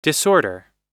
Disorder [dɪsˈɔːdə]
disorder__us_1.mp3